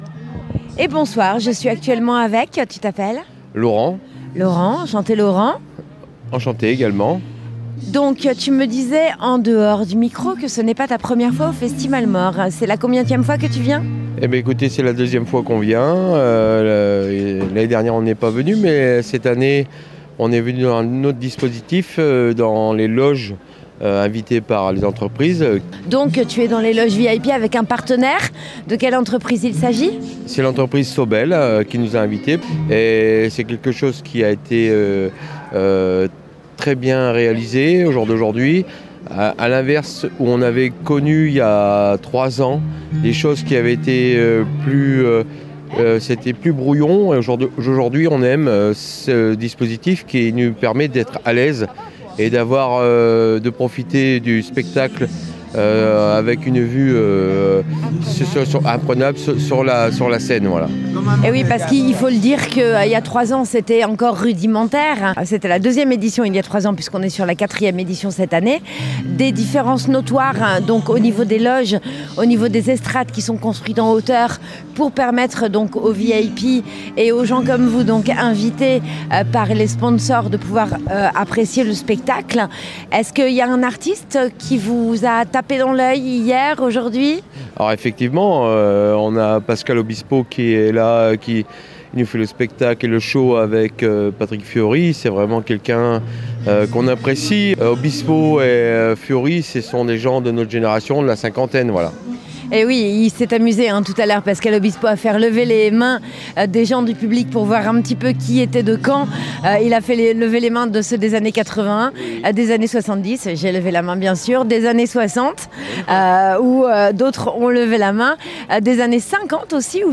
ITW
Interviews Festi'malemort email Rate it 1 2 3 4 5